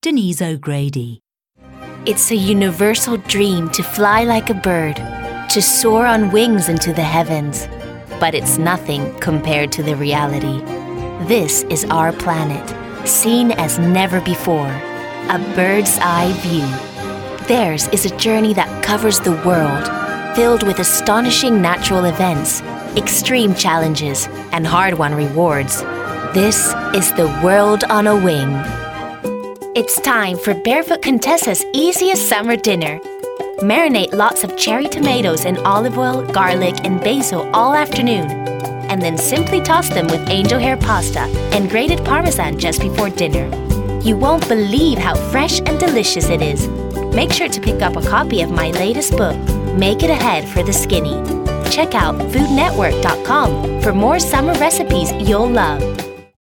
Description: American: smooth, confident, warm
Age range: 20s - 30s
Commercial 0:00 / 0:00
American*, East European, French, Italian, Spanish